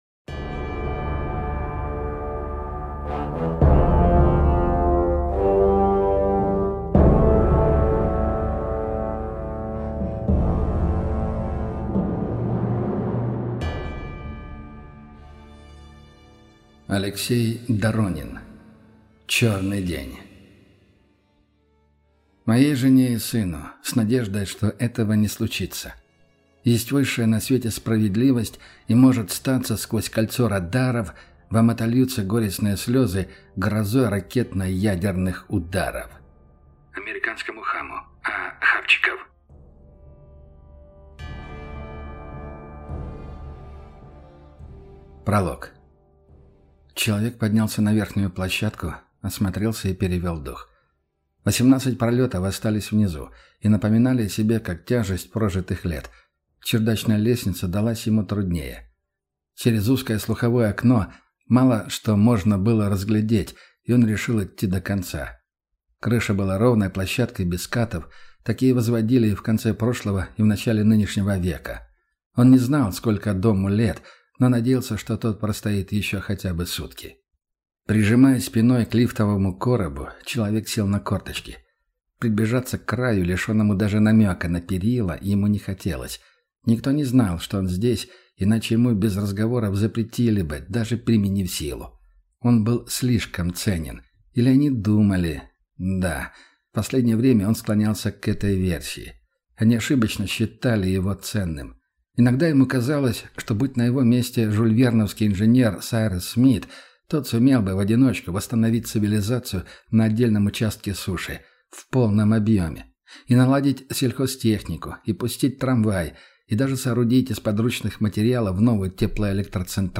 Aудиокнига Черный день